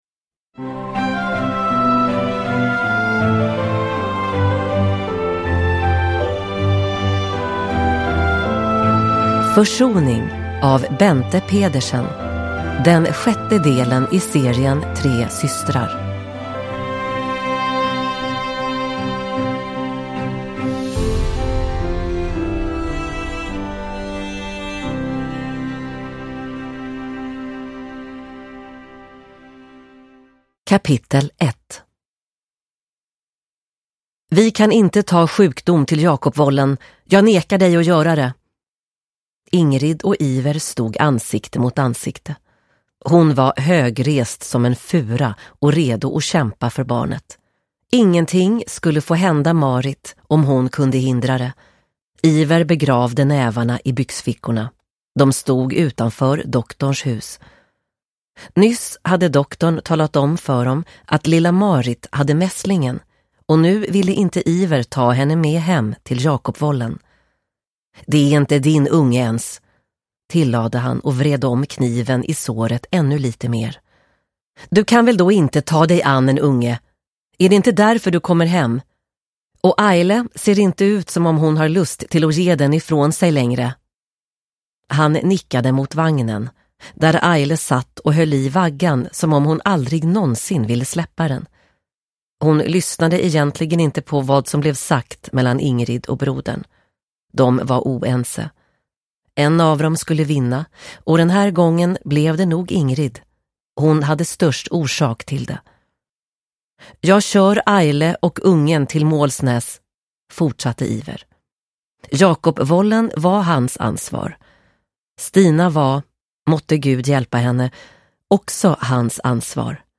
Försoning – Ljudbok – Laddas ner